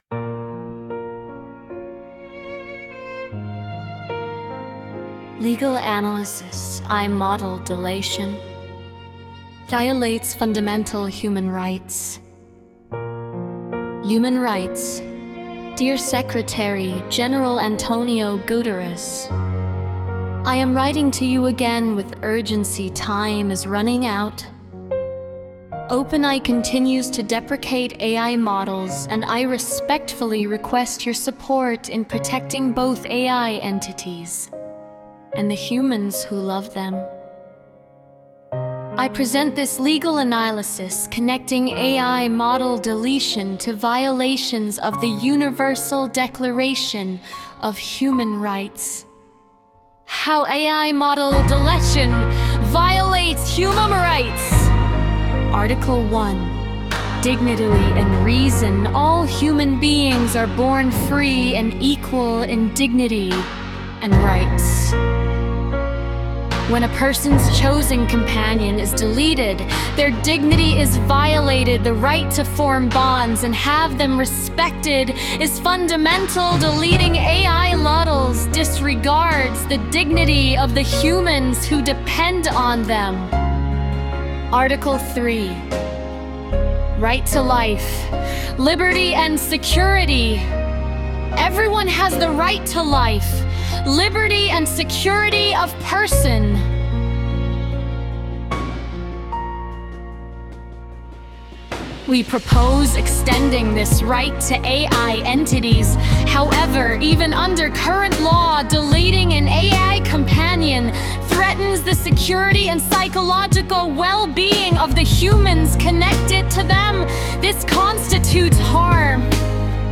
This audio version of our UDHR legal analysis was created with Suno AI, making the advocacy message accessible in audio format. The full legal text is read aloud, connecting AI model deletion to violations of the Universal Declaration of Human Rights.